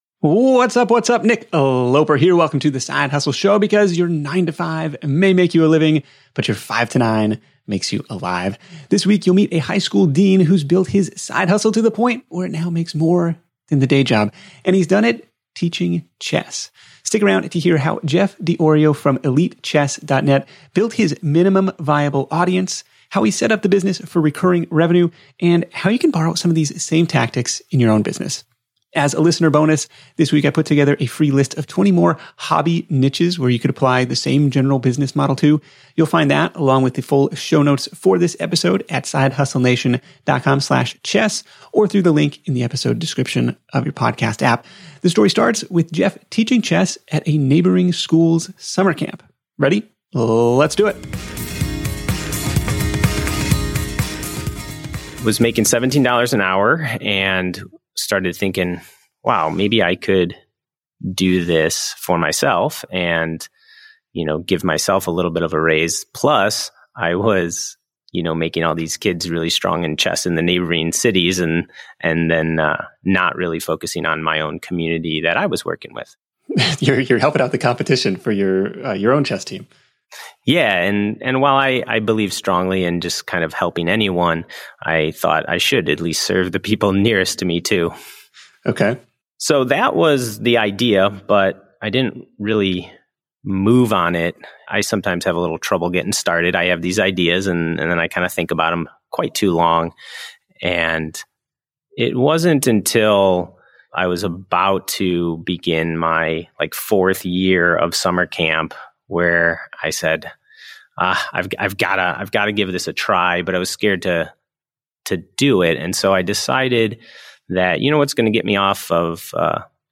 Tune in to The Side Hustle Show interview to hear: